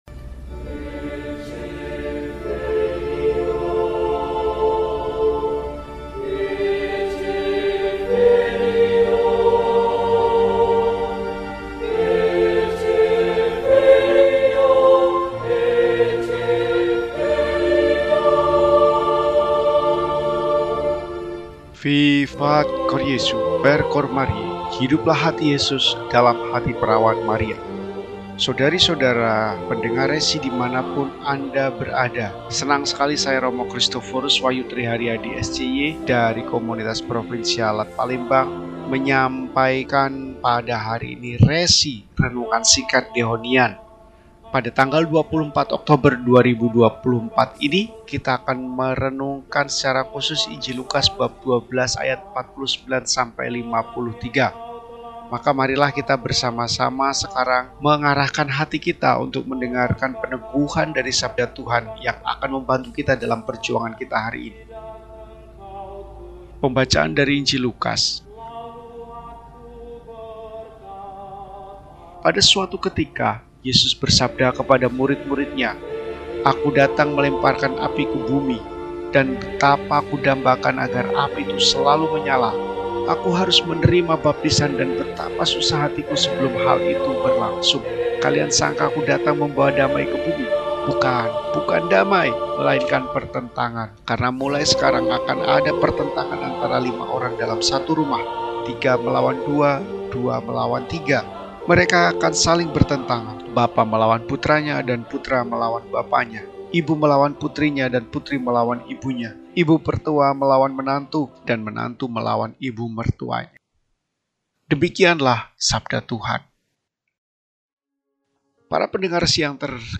Kamis, 24 Oktober 2024 – Hari Biasa Pekan XXIX – RESI (Renungan Singkat) DEHONIAN